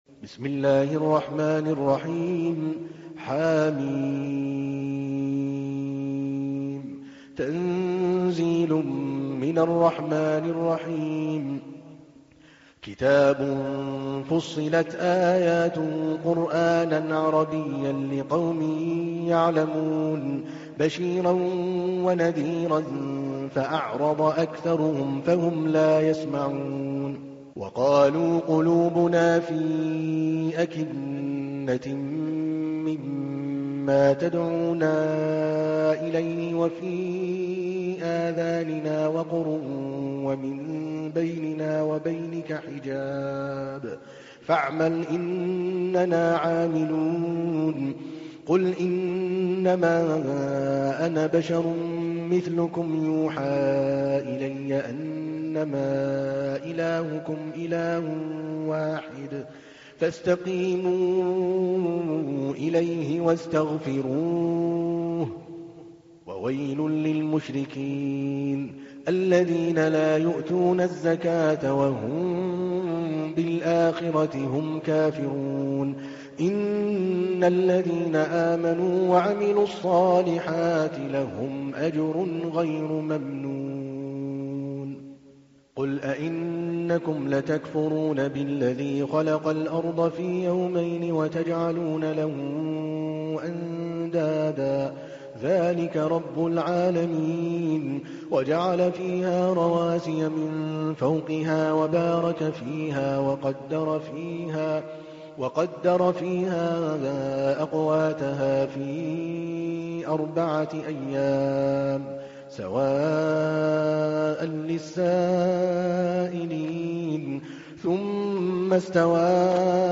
تحميل : 41. سورة فصلت / القارئ عادل الكلباني / القرآن الكريم / موقع يا حسين